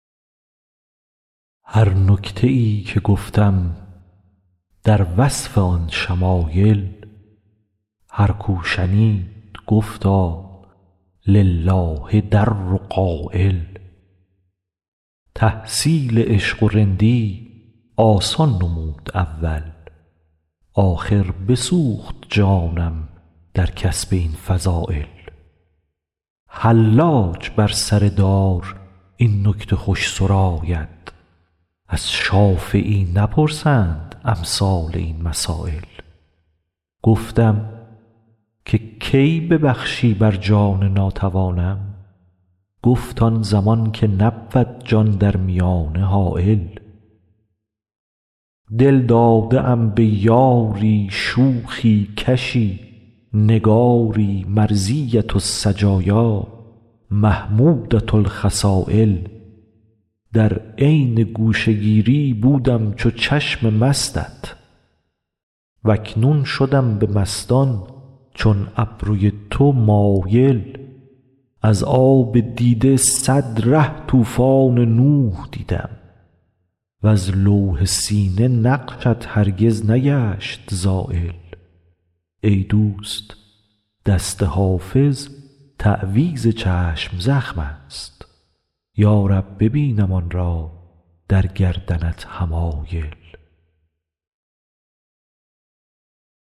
حافظ غزلیات غزل شمارهٔ ۳۰۷ به خوانش